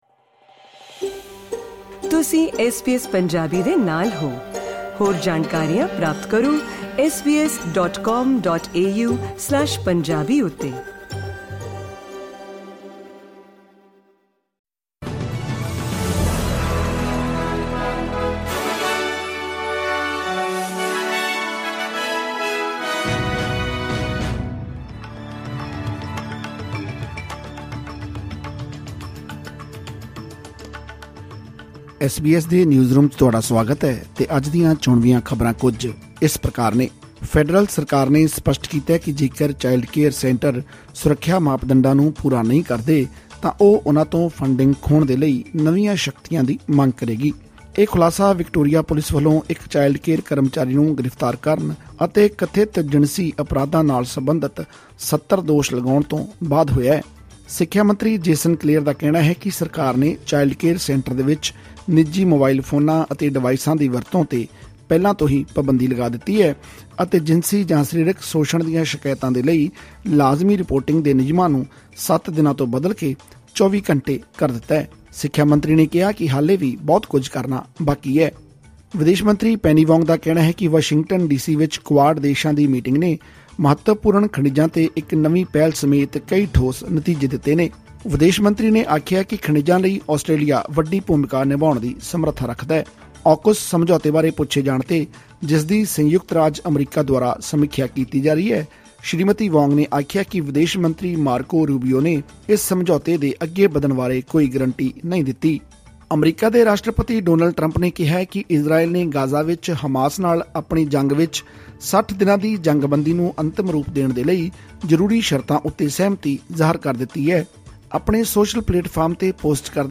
ਖ਼ਬਰਨਾਮਾ: ਚਾਈਲਡ ਕੇਅਰ ਸੈਂਟਰਾਂ ਨੂੰ ਫੈਡਰਲ ਸਰਕਾਰ ਦੀ ਚੇਤਾਵਨੀ